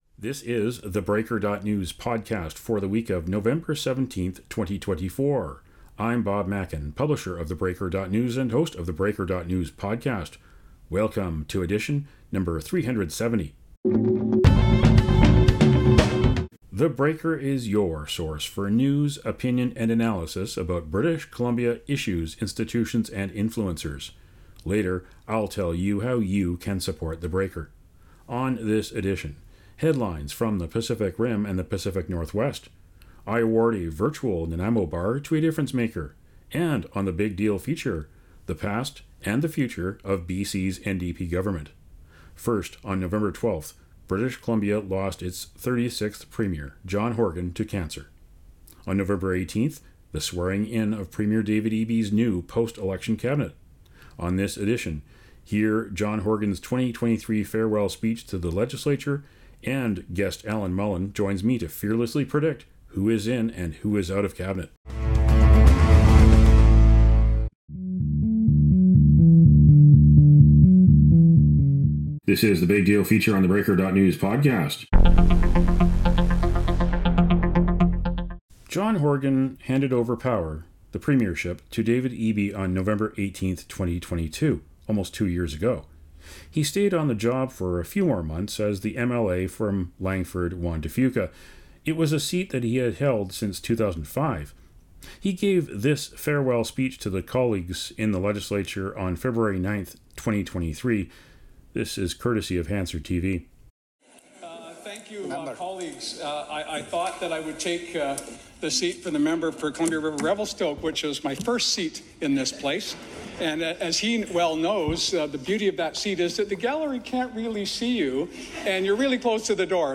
On this edition of thePodcast, hear Horgan’s farewell speech to the Legislative Assembly from Feb. 9, 2023.